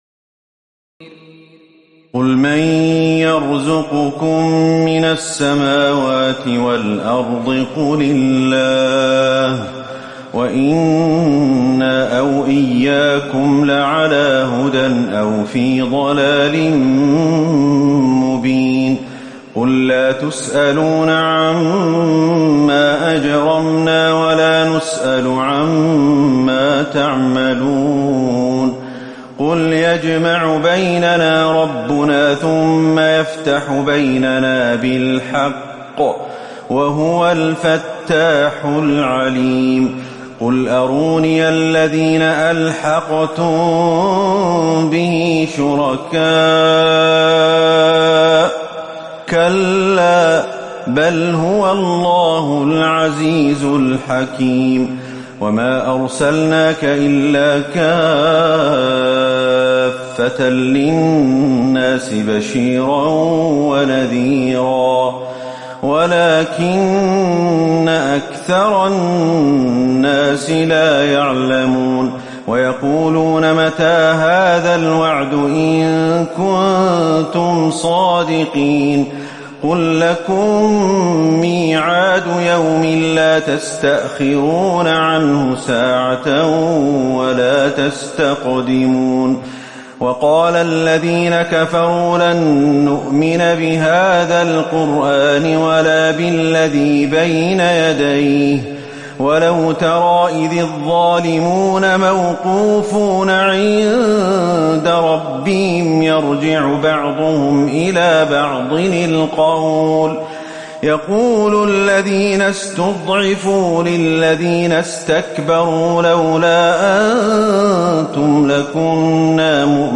تراويح ليلة 21 رمضان 1438هـ من سور سبأ (24-54) وفاطر و يس(1-32) Taraweeh 21 st night Ramadan 1438H from Surah Saba and Faatir and Yaseen > تراويح الحرم النبوي عام 1438 🕌 > التراويح - تلاوات الحرمين